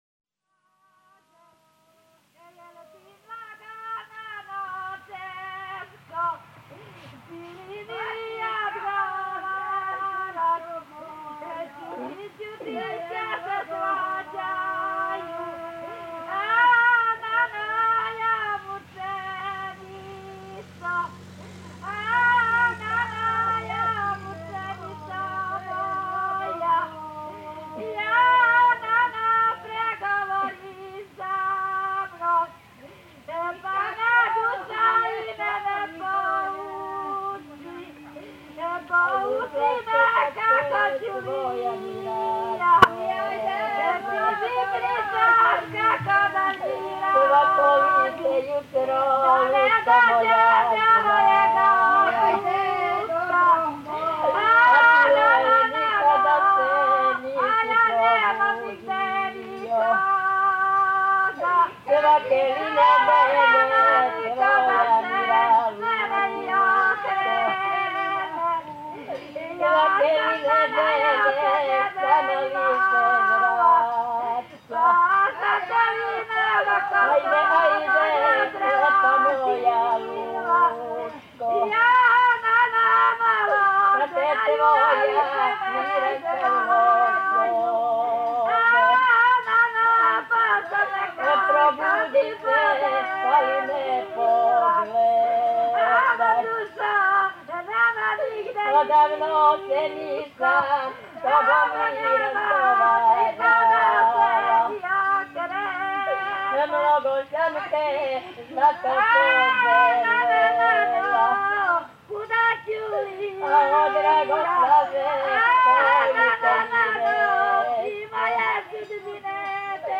Serbische Totenklage
Serbian Keen
Serbische_Totenklage.mp3